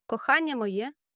ko-KHAH-nya mo-YE my love